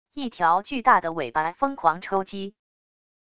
一条巨大的尾巴疯狂抽击wav音频